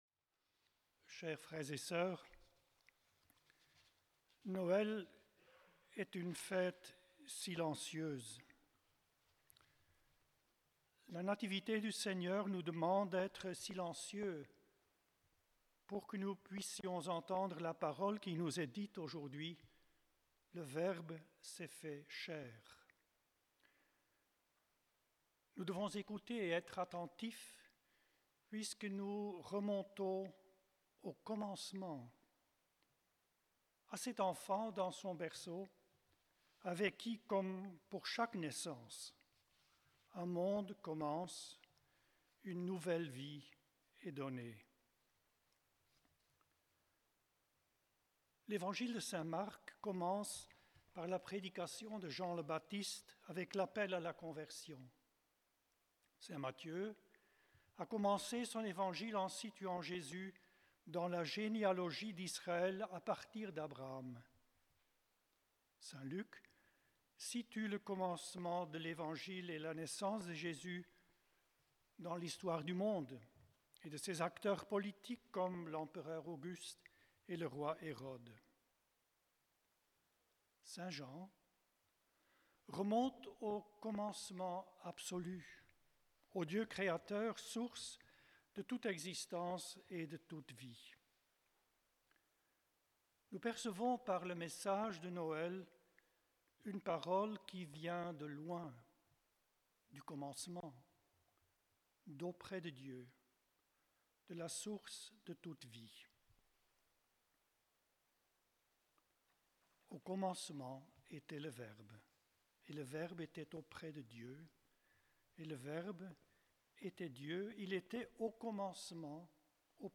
Enregistrement en direct